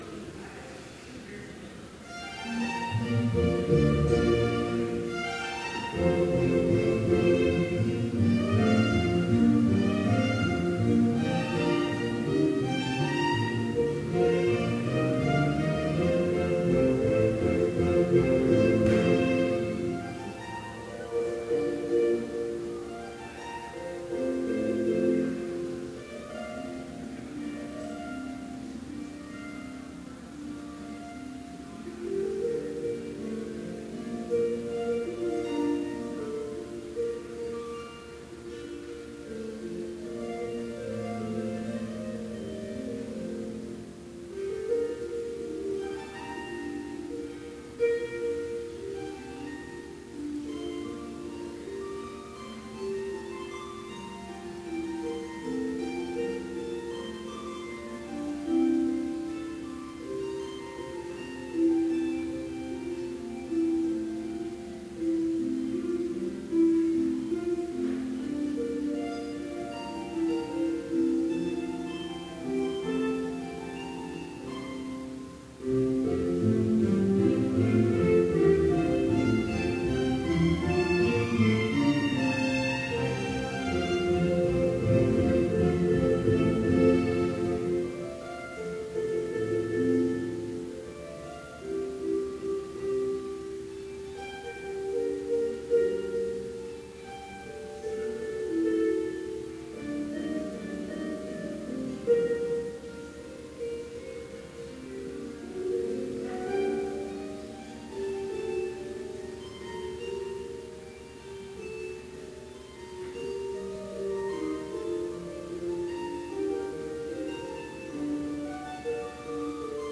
Complete service audio for Chapel - March 22, 2018